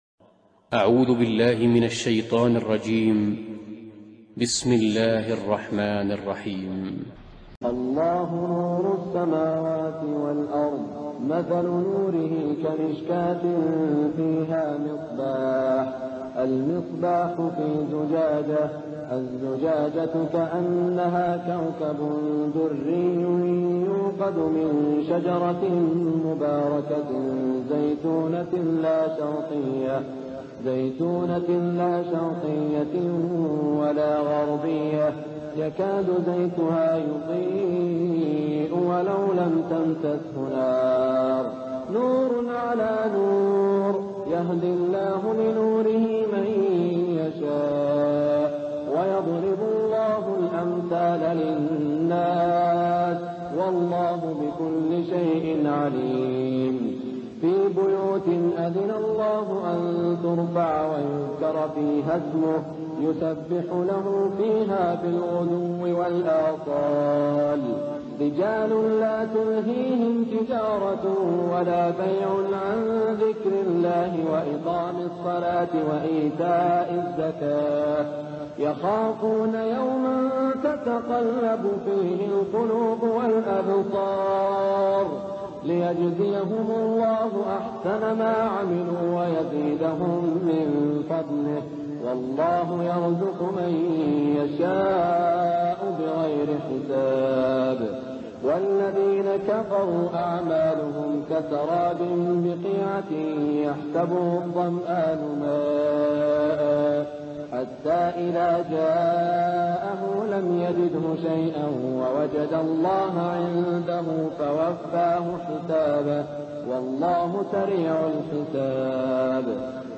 تلاوة من سورة النور من عام ١٤٠٩ | بمدينة الرياض > تلاوات الشيخ سعود الشريم خارج الحرم > تلاوات و جهود الشيخ سعود الشريم > المزيد - تلاوات الحرمين